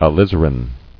[a·liz·a·rin]